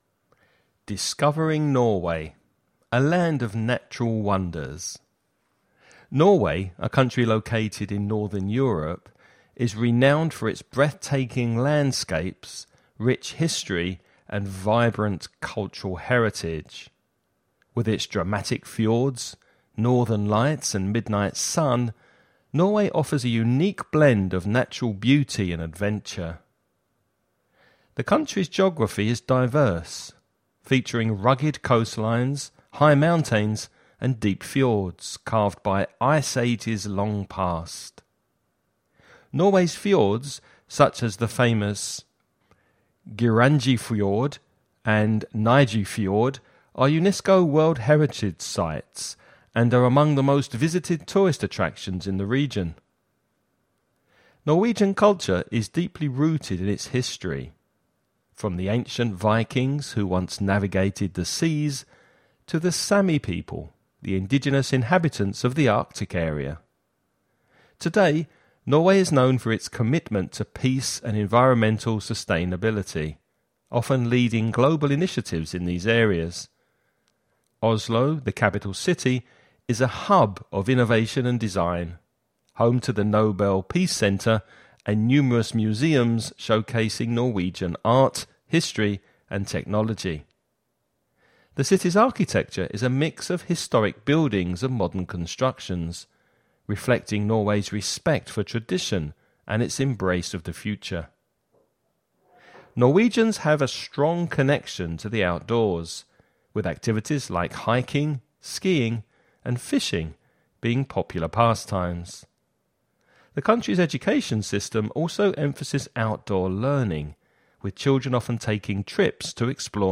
You’re going to listen to a man talking about the country of Norway.